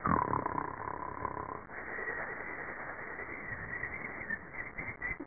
Amiga 8-bit Sampled Voice
snore4.mp3